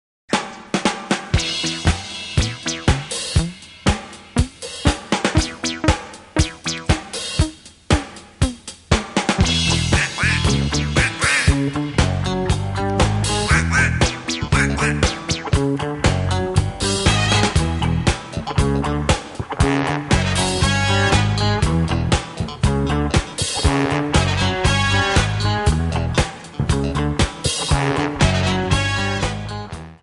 Backing track files: 1970s (954)
(fade out)